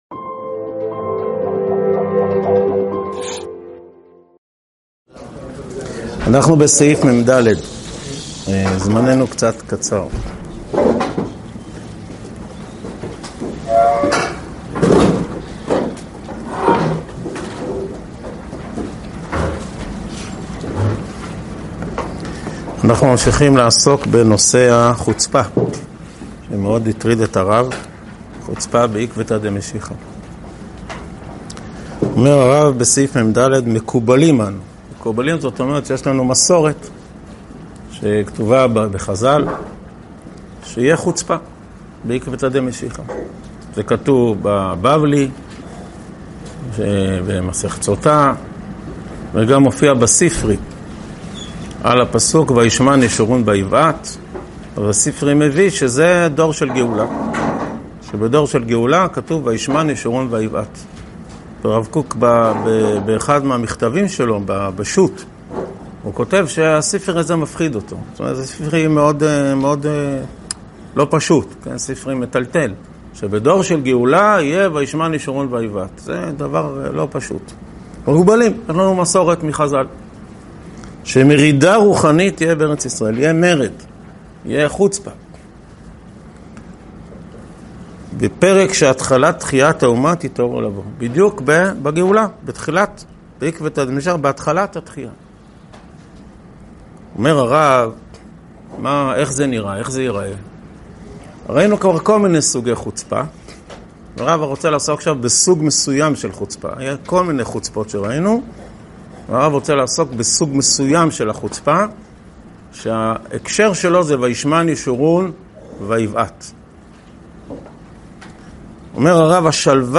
הועבר בישיבת אלון מורה בשנת תשפ"ו.